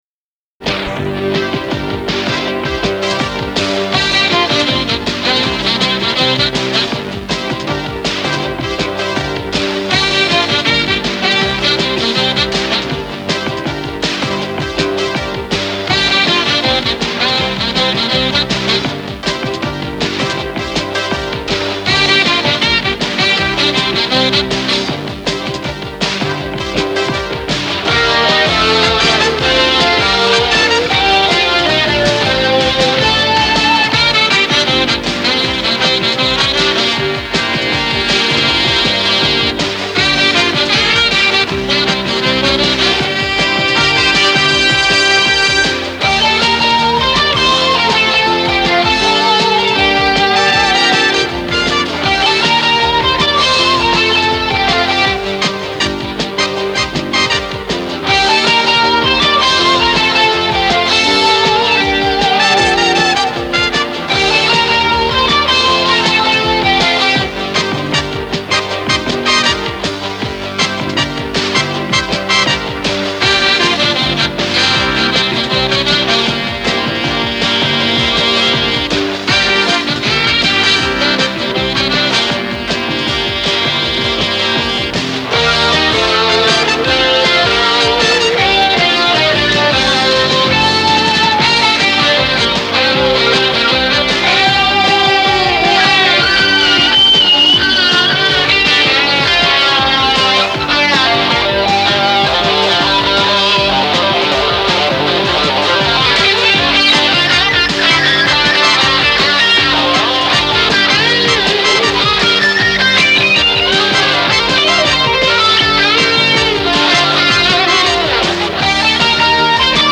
В моно-варианте.